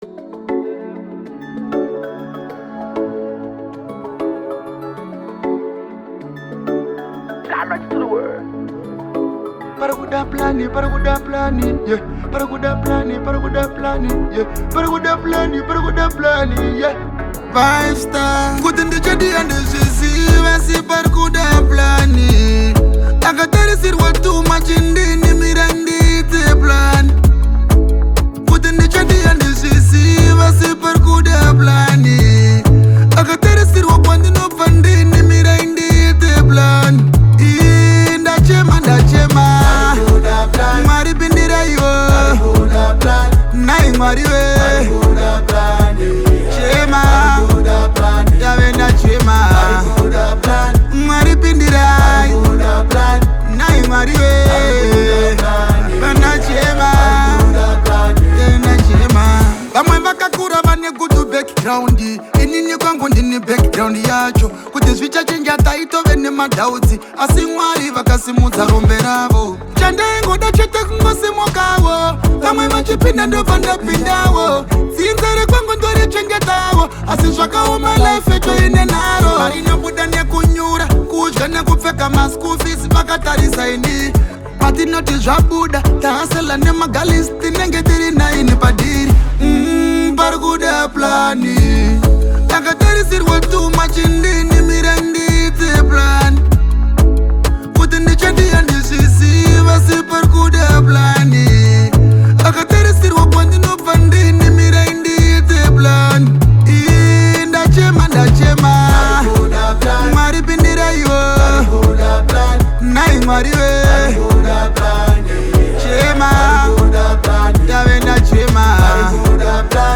smooth vocals
blends Afrobeat with contemporary sounds
With its high energy tempo and catchy sounds